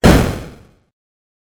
explo2.wav